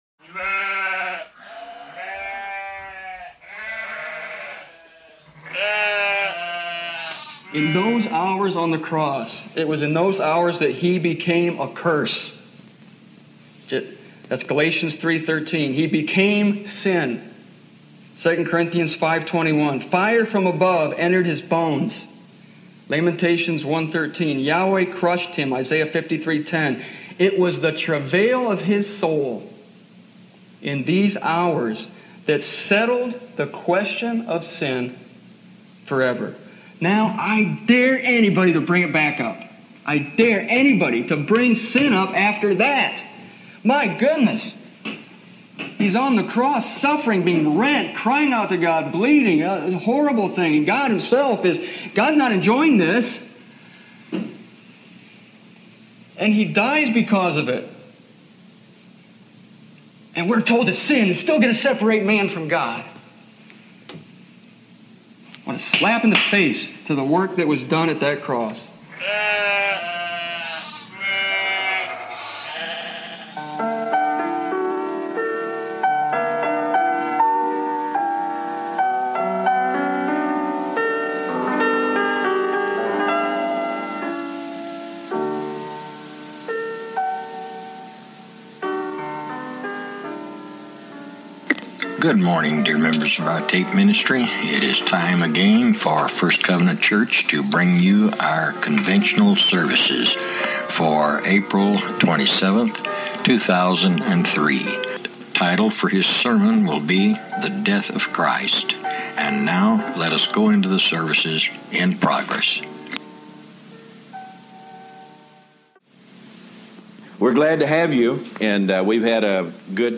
I recorded this message in Irving, Texas in 2003.